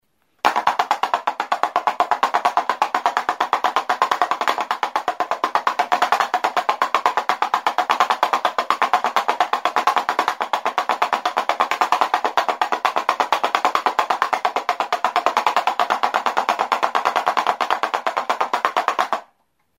Enregistr� avec cet instrument de musique.
BOLANG-GU; Jirabirako danbor kirtenduna; Rattle drum
Larruzko bi partxe dituen danbortxo sasizilindrikoa da. Zurezko kirtena du eta alboetan sokatxoekin zintzilikaturik bi bolatxo ditu.